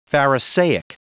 Prononciation du mot pharisaic en anglais (fichier audio)
Prononciation du mot : pharisaic